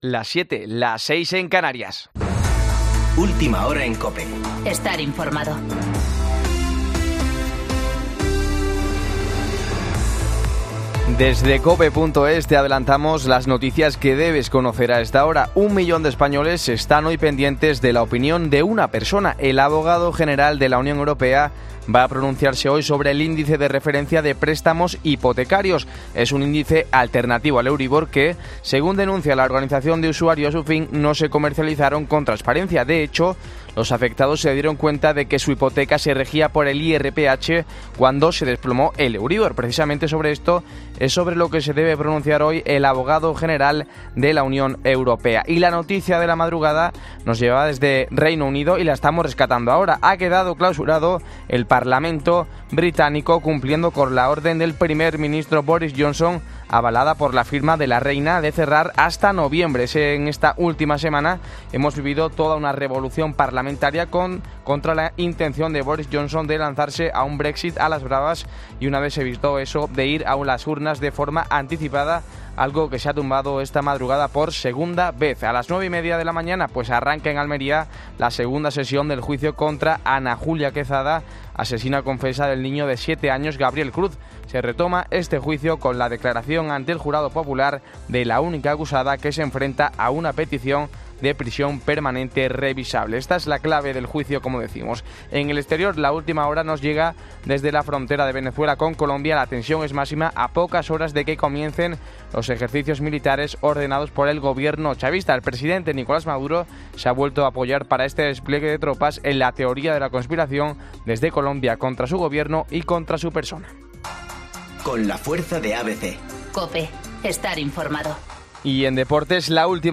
Boletín de noticias COPE del 10 de septiembre a las 07.00